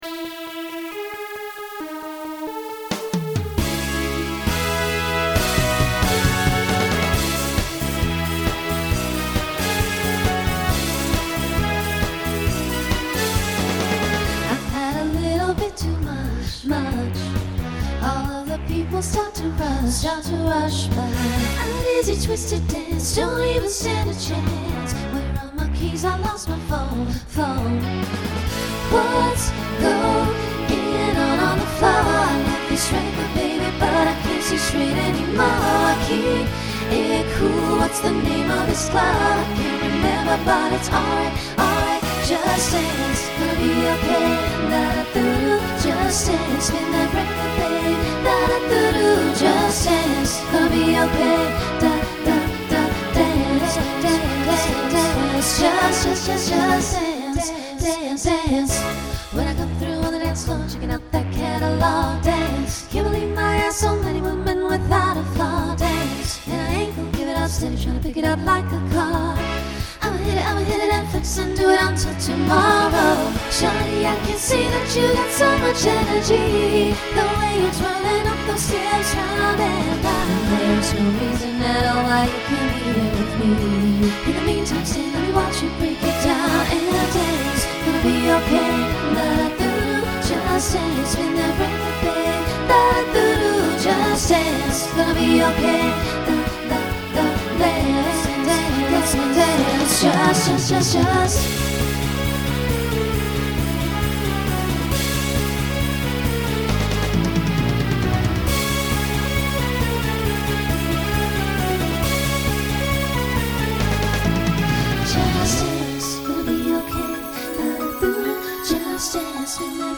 New SSA voicing for 2020